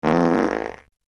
28. Ежедневный пук
ejednevnyi-puk.mp3